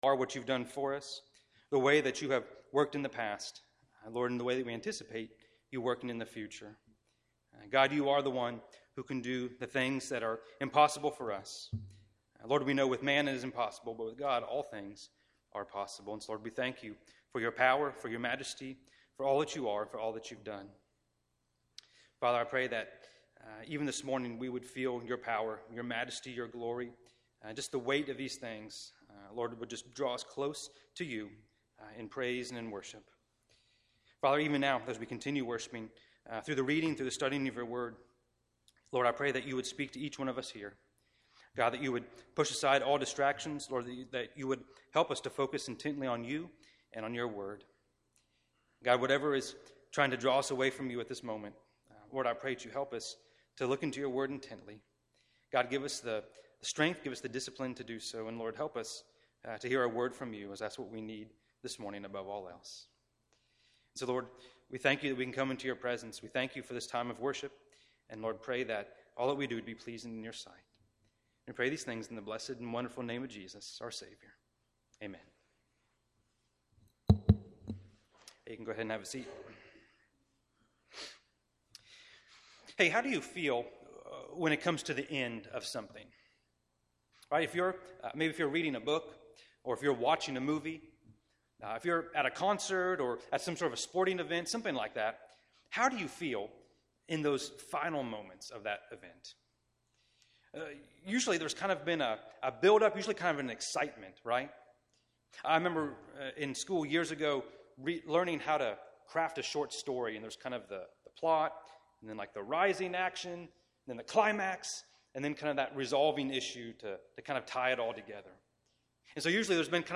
Past Sermons - Chinese Baptist Church of Miami